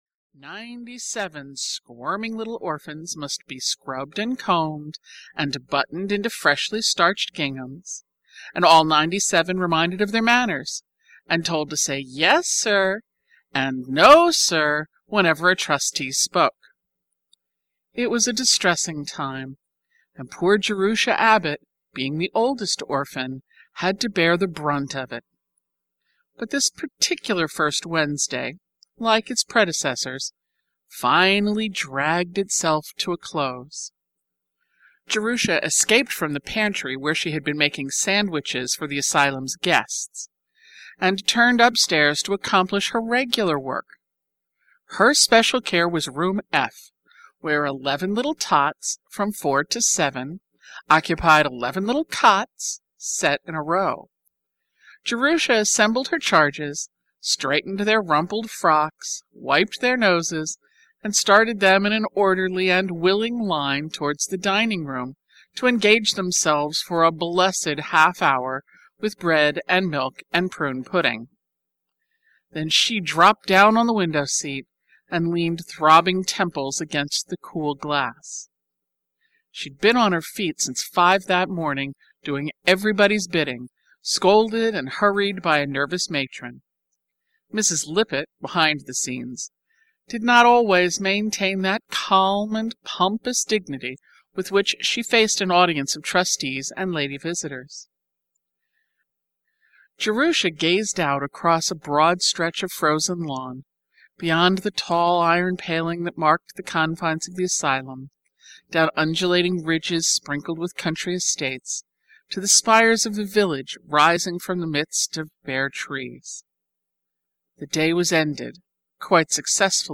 Daddy-Long-Legs (EN) audiokniha
Ukázka z knihy